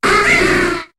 Cri de Cornèbre dans Pokémon HOME.